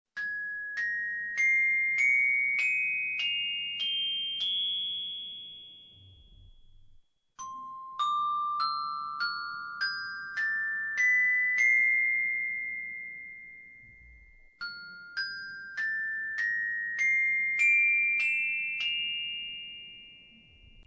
Ascoltiamo queste tre scale: